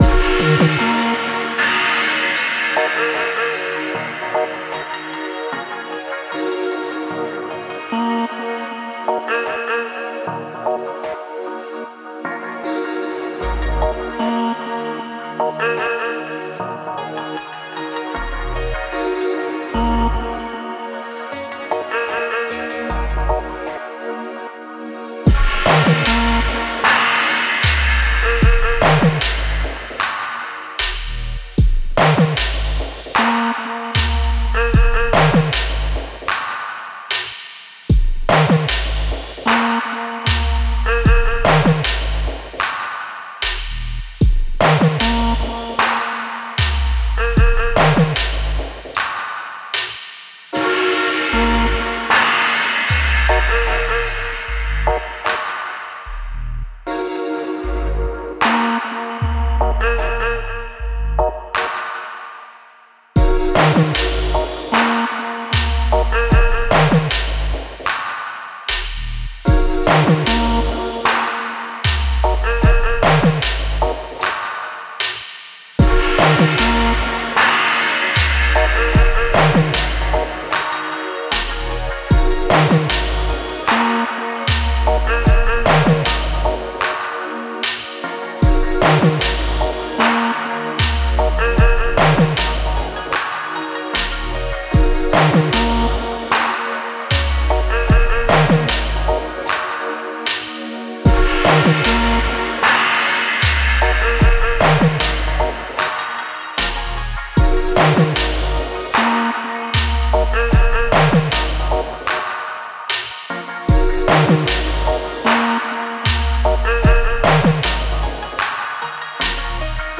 Genre:Dub
トリップホップ、ダウンテンポ、ダブが溶け合った、深みのあるサウンドコレクション。
デモサウンドはコチラ↓
Key: Eb minor